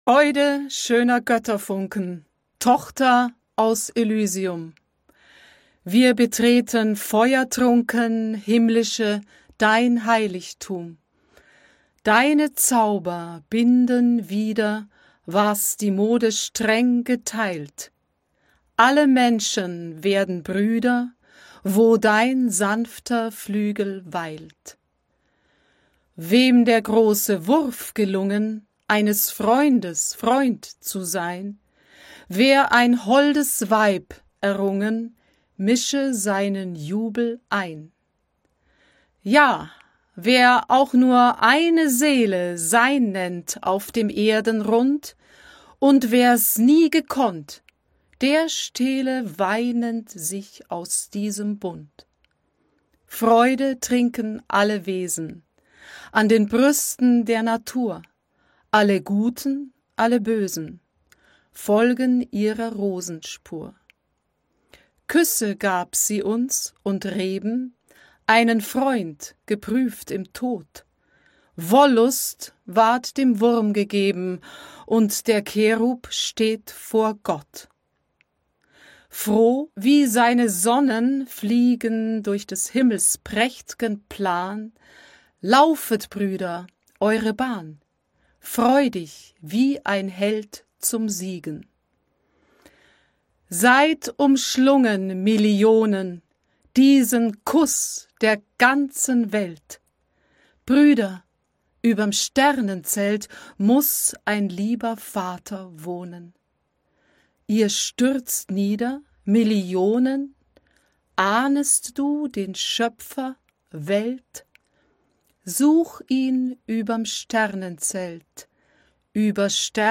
Beethovens-Symphony-No.-9-Finale-A-Pronunciation-Guide-for-Choral-Singers-02.mp3